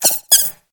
Cri de Ouistempo dans Pokémon HOME.